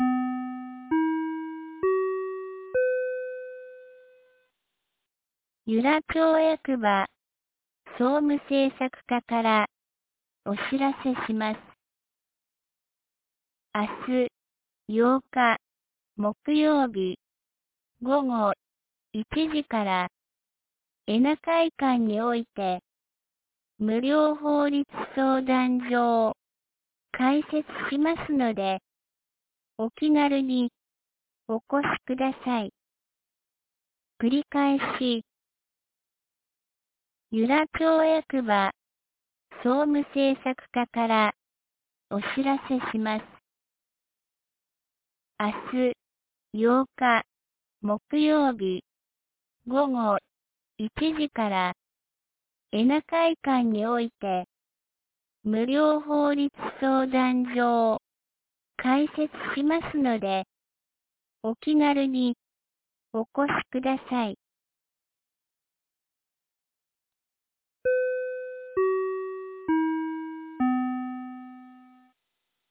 2019年08月07日 17時06分に、由良町より全地区へ放送がありました。
放送音声